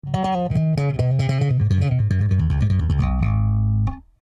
Warwick Corvette 6 strings slap